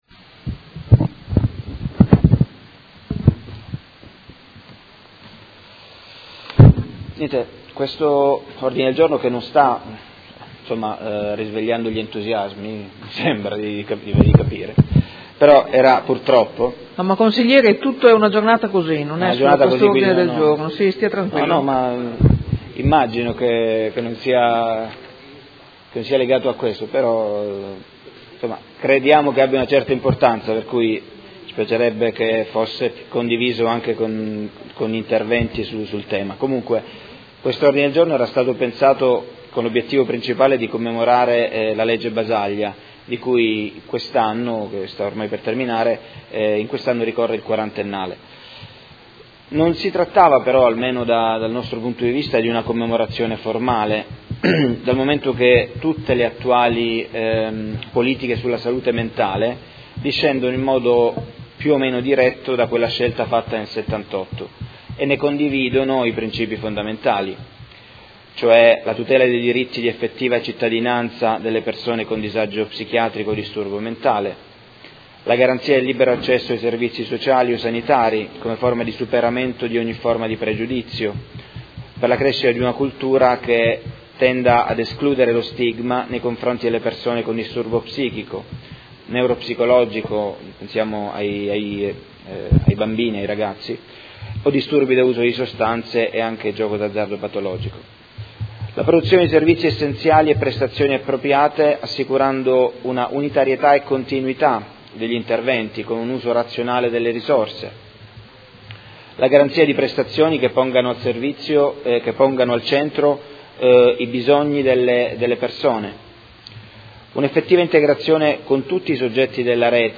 Dibattito su Ordine del Giorno presentato dai Consiglieri Fasano, Baracchi, Bortolamasi, Forghieri, Arletti, Lenzini, Venturelli, Morini, Di Padova, Pacchioni, Liotti, De Lillo e Poggi (PD) avente per oggetto: La salute mentale a Modena nel quarantennale della “Legge Basaglia”